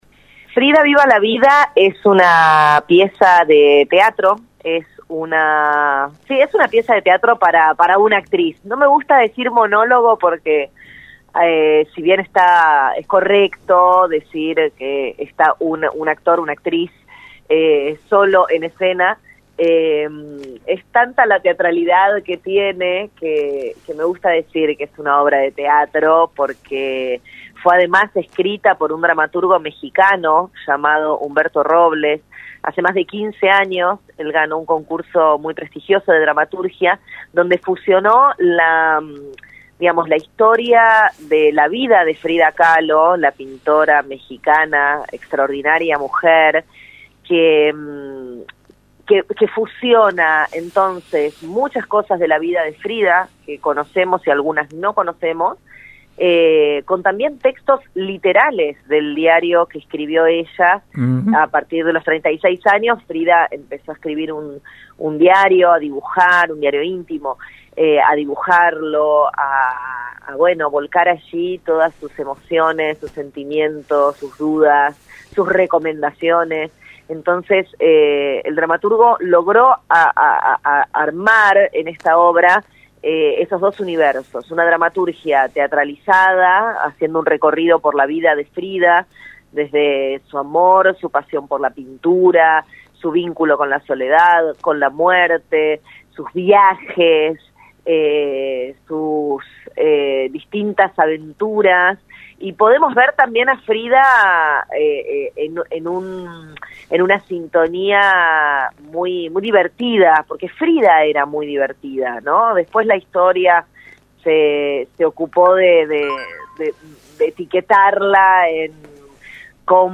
LAURA AZCURRA EN RADIO UNIVERSO 93 1